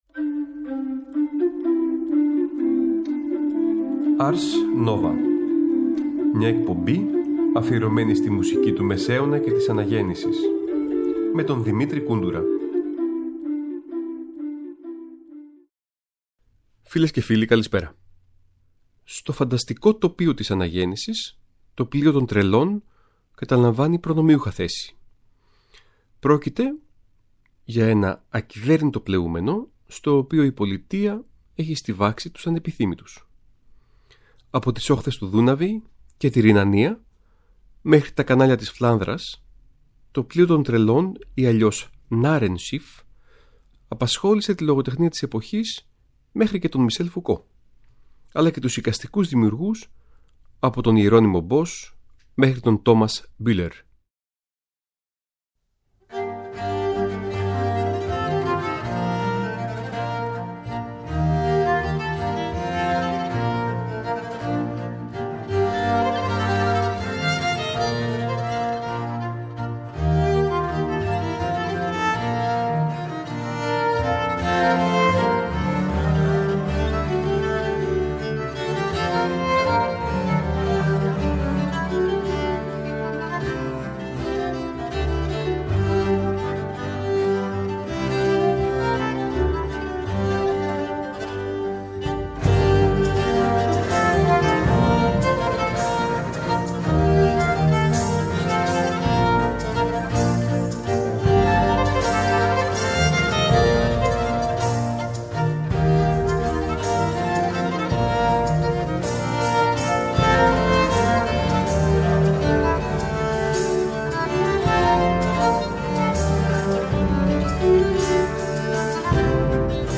Ισπανική & Γερμανική Αναγέννηση – Σεφαραδίτικη Παράδοση στα Βαλκάνια
Μουσική της Αναγεννησιακής Ισπανίας αλλά και της Γερμανίας και της Αγγλίας καθώς και σεφαραδίτικη παράδοση των Βαλκανίων σε Λαντίνο.